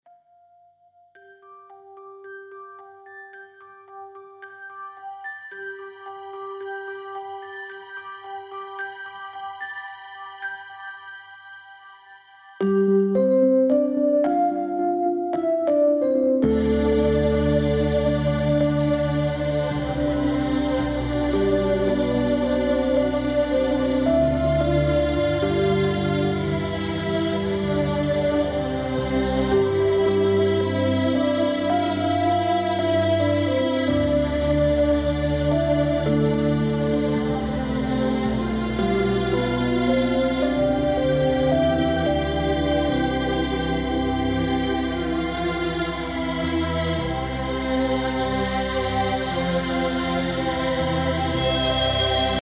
Композиция с оркестром
Друзья оцените, пожалуйста, настройки пространства данной композиции.
Она не полностью еще сведена, но с пространством по мне все закончено.